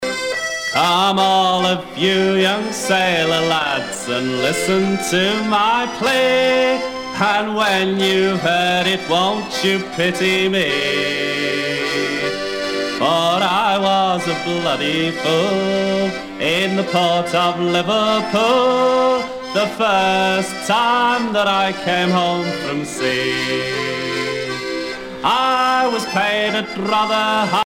Pièce musicale éditée